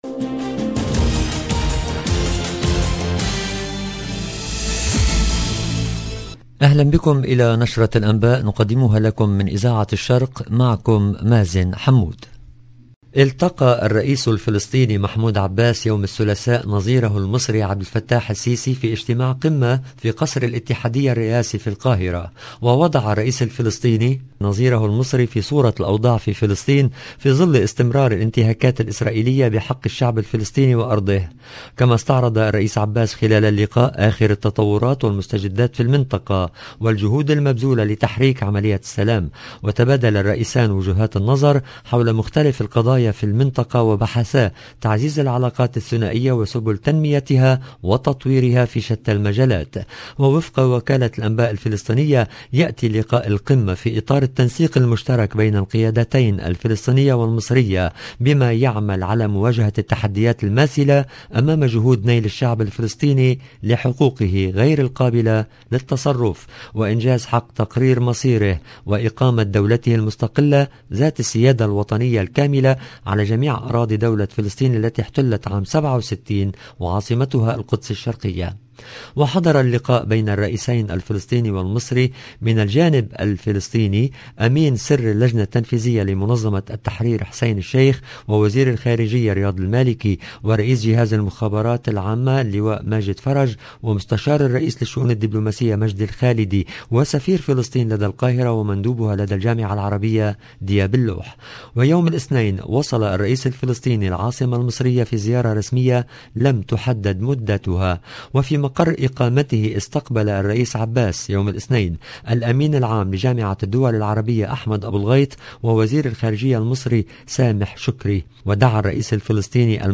LE JOURNAL DU SOIR EN LANGUE ARABE DU 6/09/22